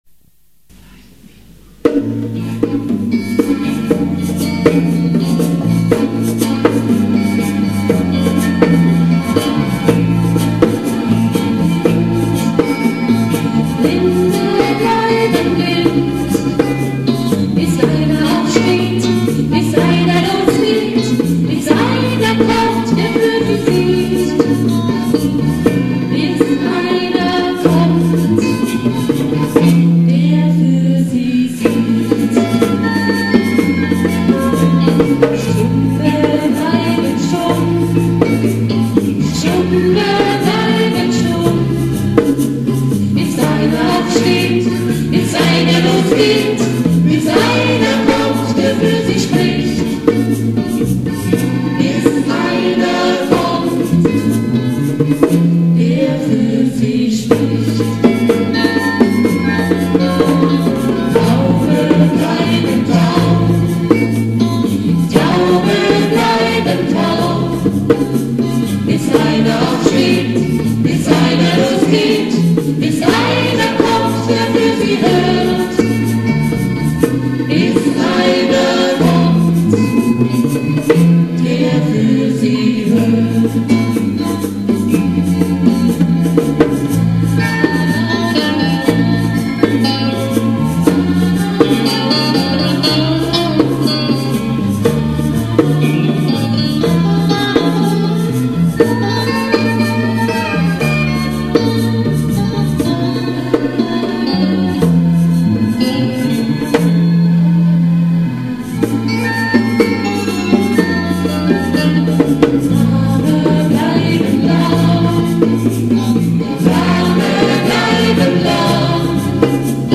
alle Aufnahmen sind "live" in der Kirche mitgeschnitten und deshalb keine CD-Qualität